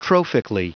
Prononciation du mot trophically en anglais (fichier audio)
Prononciation du mot : trophically
trophically.wav